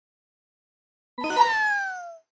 marioYay.mp3